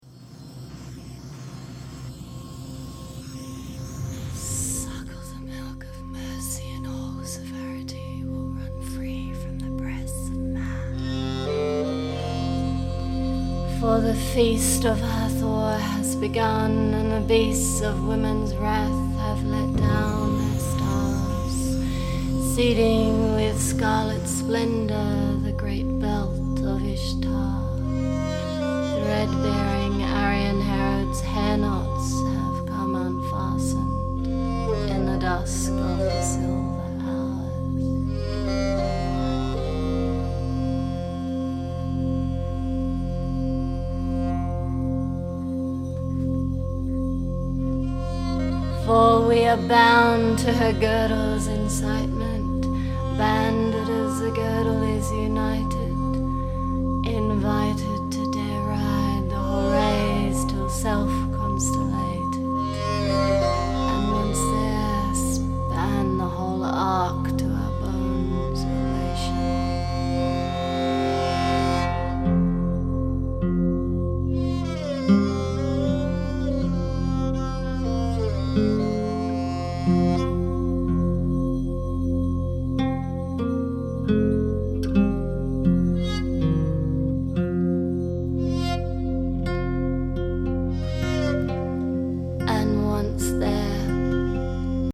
Esraj, tabla, guitar, keyboard and Curtan
Violin and singing-bell